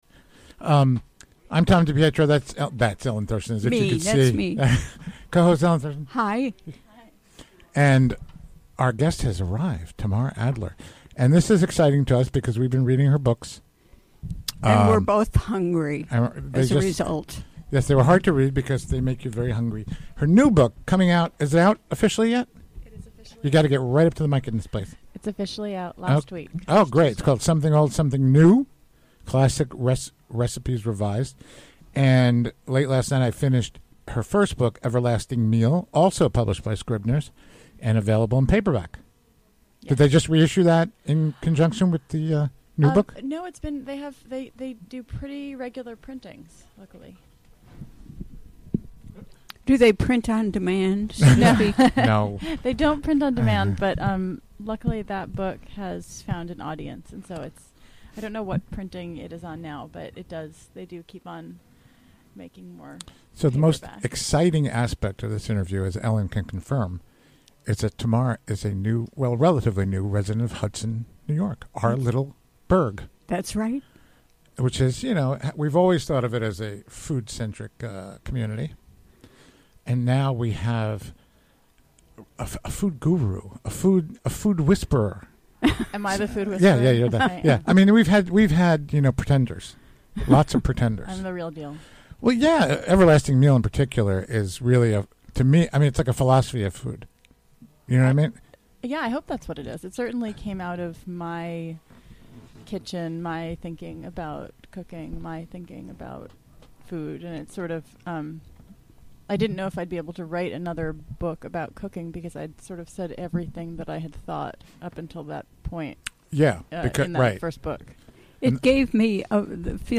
Recorded live during the WGXC Afternoon Show Thursday, April 12, 2018.